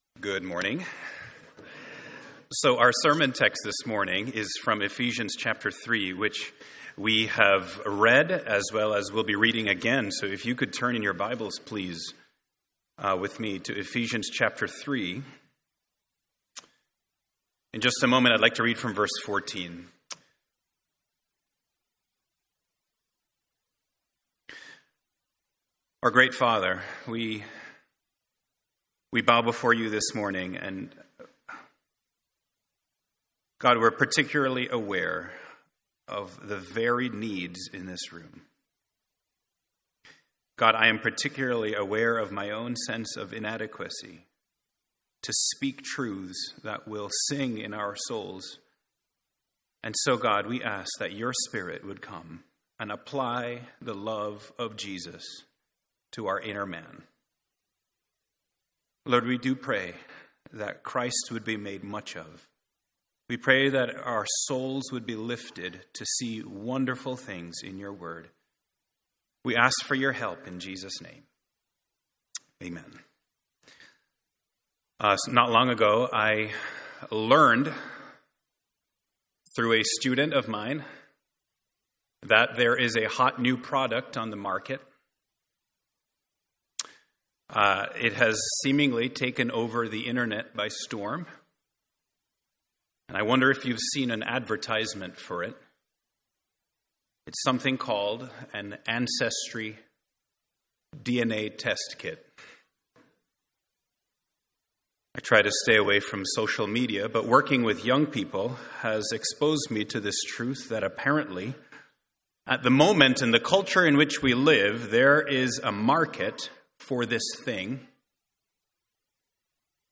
Sermons on Ephesians 3:14-21 — Audio Sermons — Brick Lane Community Church